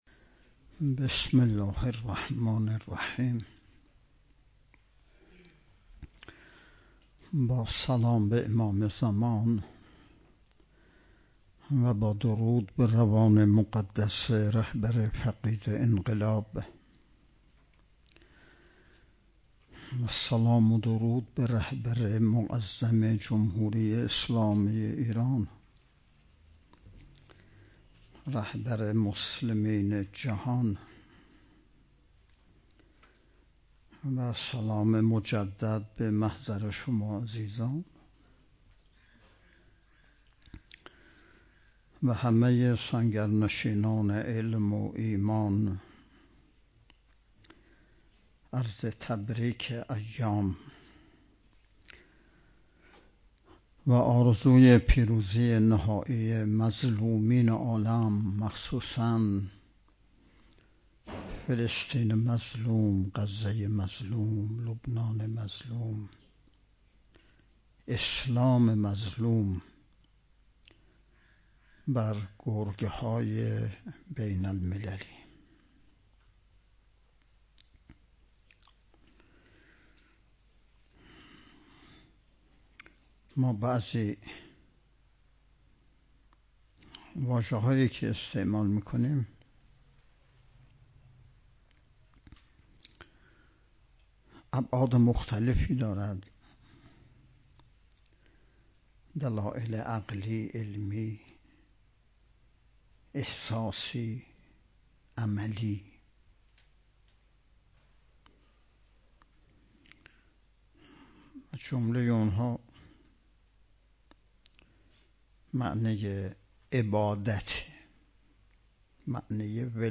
هجدهمین نشست ارکان شبکه تربیتی صالحین بسیج با موضوع تربیت جوان مؤمن انقلابی پای کار، صبح امروز ( ۸ خرداد) با حضور و سخنرانی نماینده ولی فقیه در استان، برگزار شد.